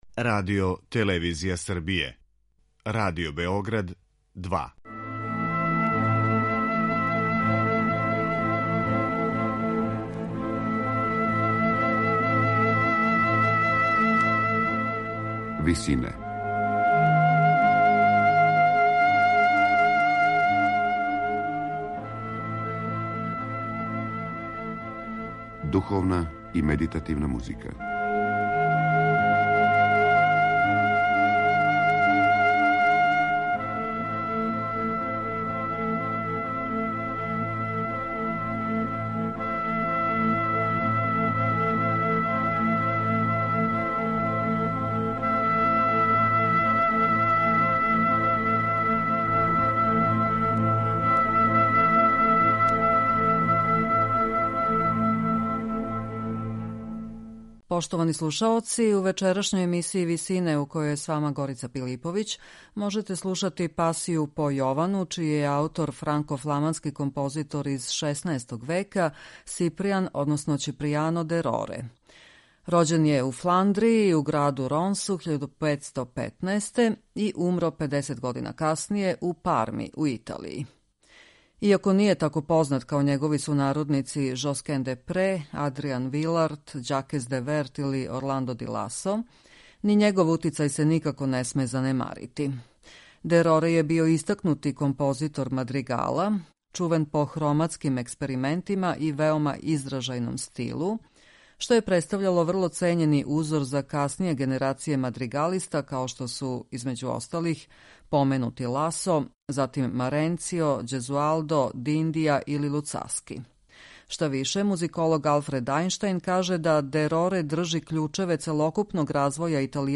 Духовна музика мајстора мадригала